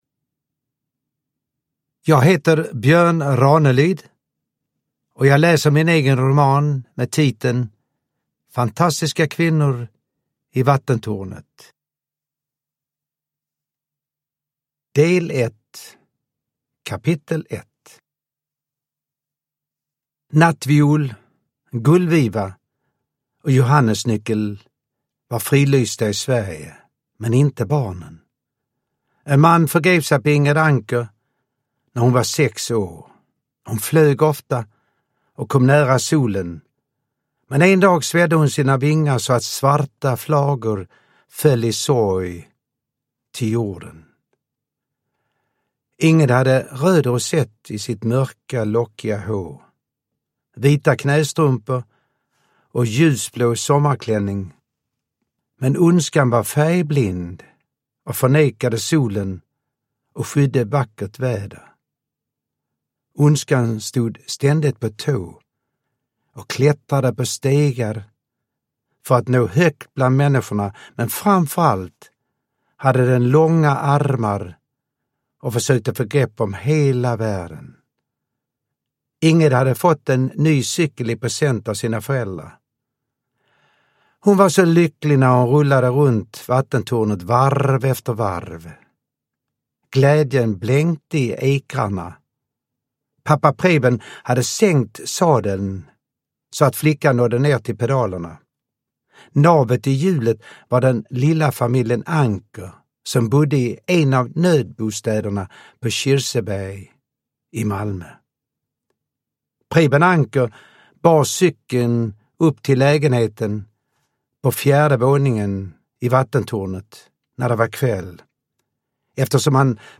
Uppläsare: Björn Ranelid
Ljudbok